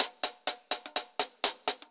Index of /musicradar/ultimate-hihat-samples/125bpm
UHH_AcoustiHatC_125-01.wav